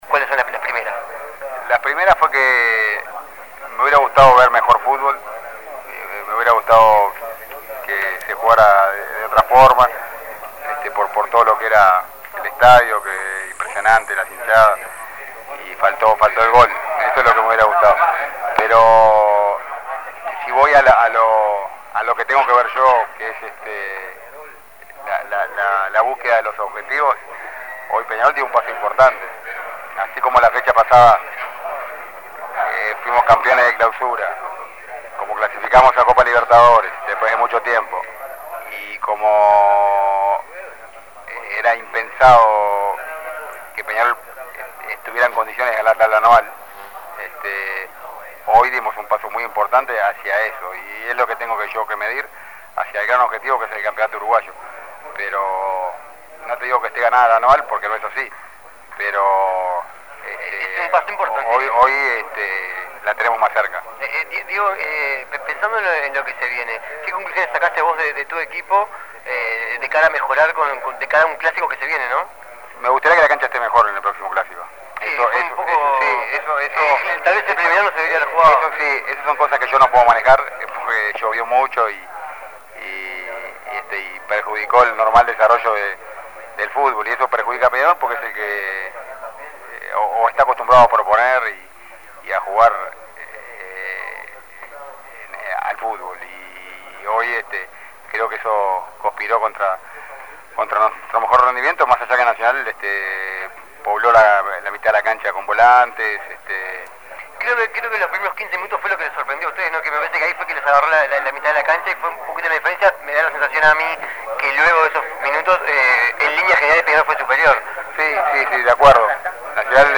El director técnico de Peñarol habló con 13 a 0 después del empate clásico. Sus sensaciones, su análisis del partido, las finales del uruguayo y el futuro mirasol fueron alguno de los temas que hizo referencia.
Testimonios Diego Aguirre: " Me hubiera gustado ver mejor fútbol" Imprimir A- A A+ El director técnico de Peñarol habló con 13 a 0 después del empate clásico.